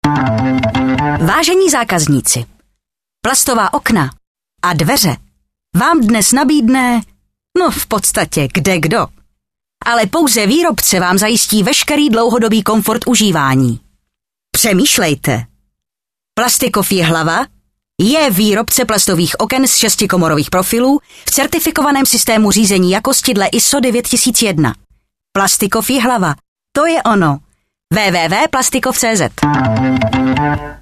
Slogan na radiu Vysočina č.3 - ISO